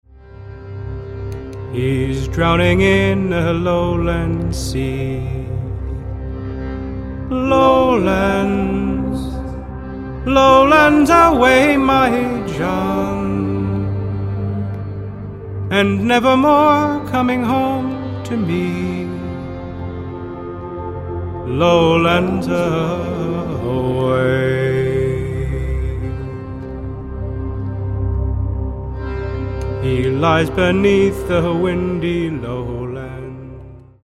- traditional Newfoundland ballad, performed with ambience